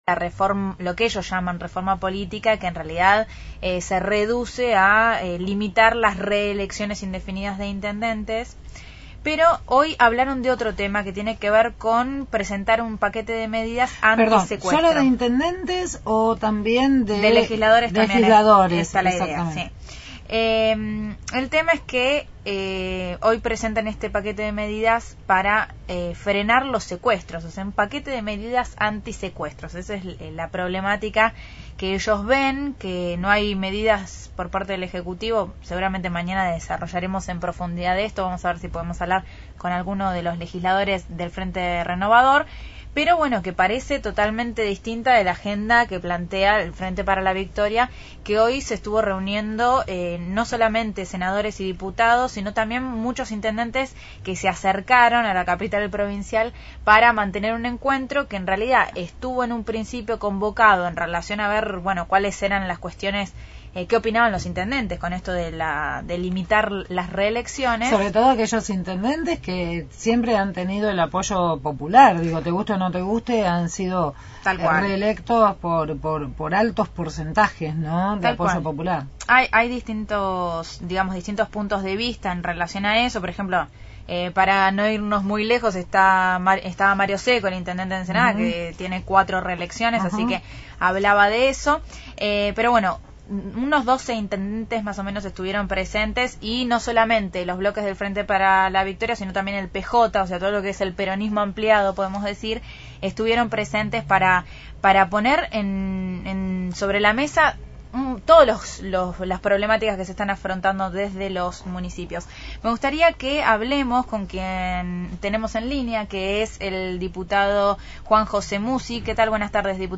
Entrevista diputado provincial Juan José Mussi